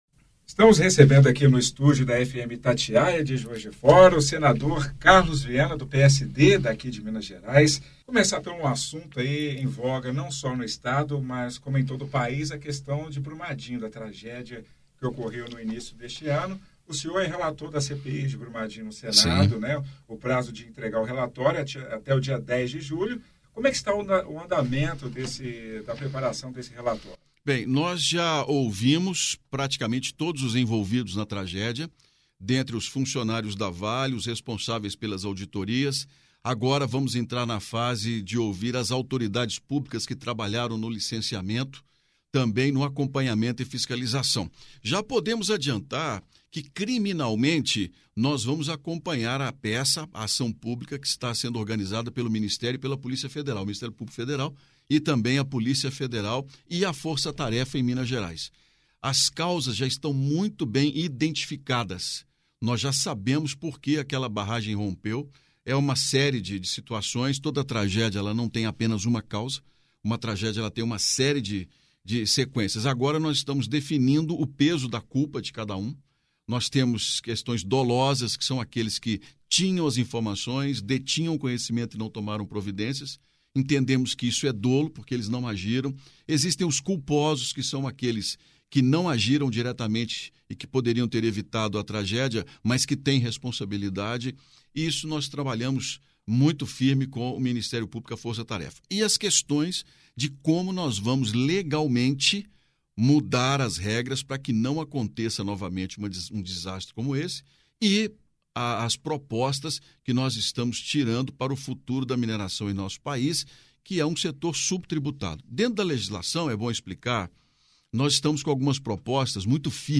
Em passagem por Juiz de Fora nesta sexta-feira, 3, o relator da CPI de Brumadinho, senador Carlos Viana (PSD-MG) concedeu entrevista à FM Itatiaia.
entrevista completa – senador Carlos Viana
ENTREVISTA-VIANA-completa.mp3